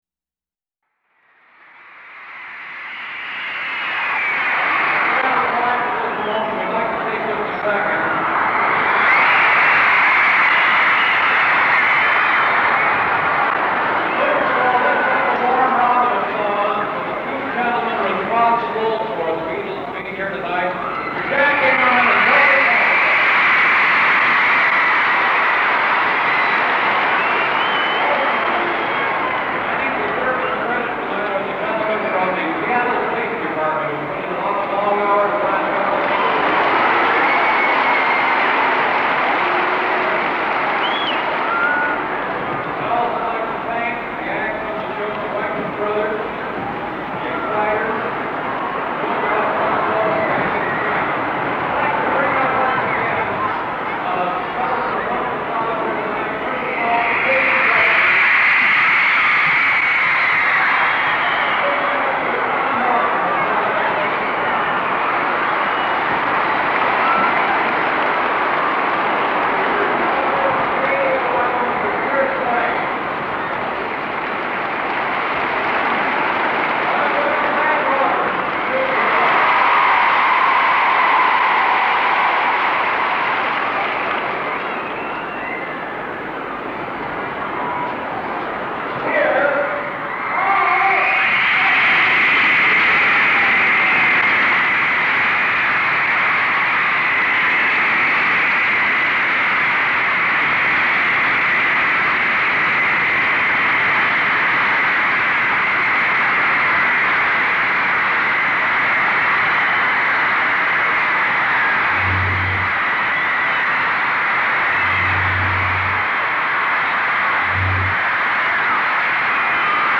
Seattle Center Coliseum, Seattle, WA; August 21, 1964